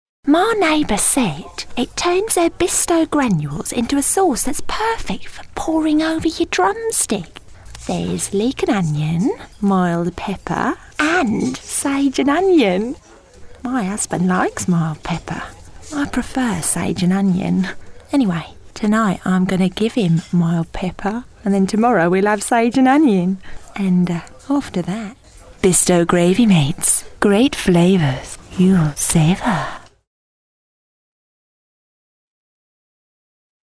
Chatty, youthful Valleys tones. From Swansea to South London with a touch of Chigwell in between. Commercials & characters.